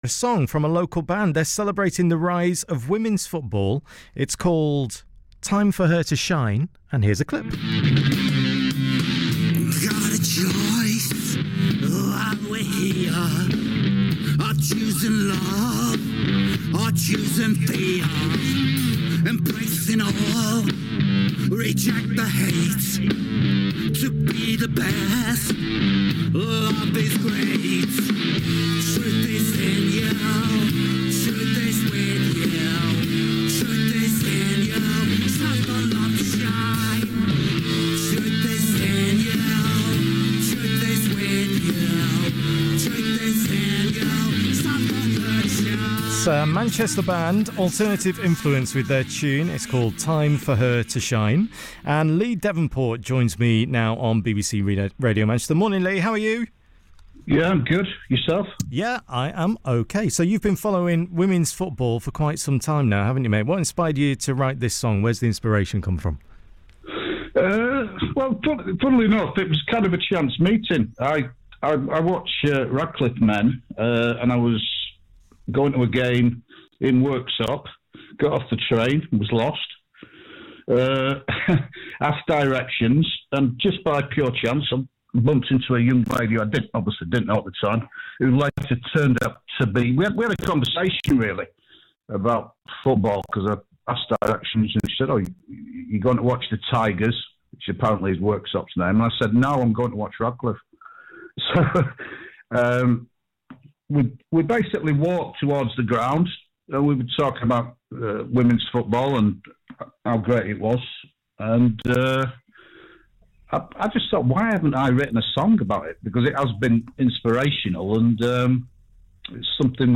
INTERVIEW WITH NEW YORK RADIO STATION iHEART